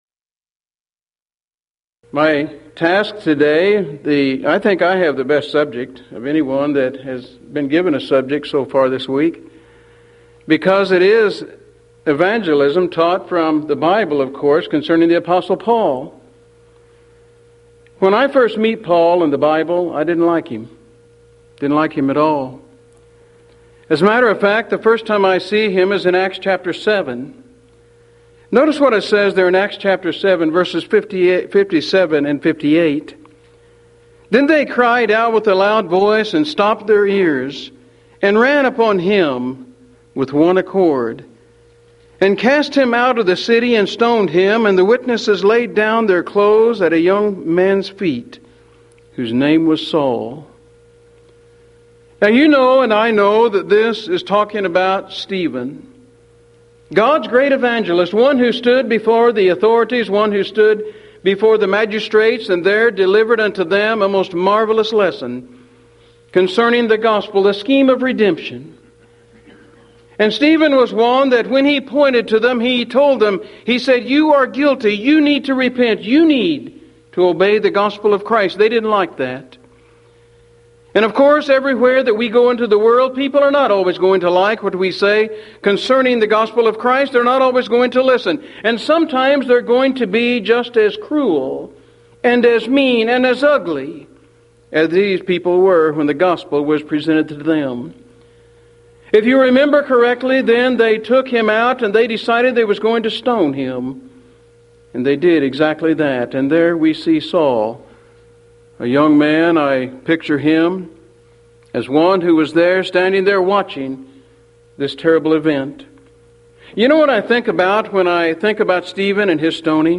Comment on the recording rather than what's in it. Event: 1994 Mid-West Lectures